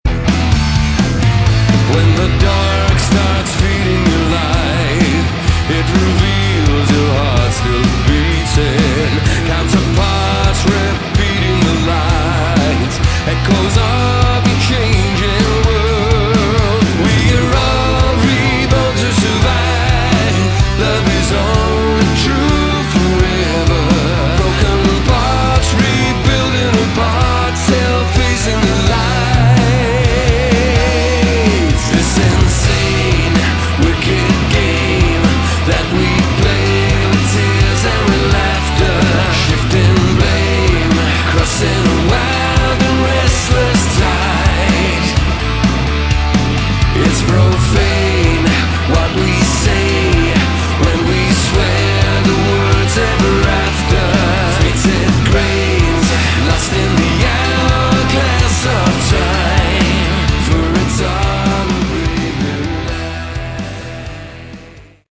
Category: AOR
guitars
keyboards
drums
backing vocals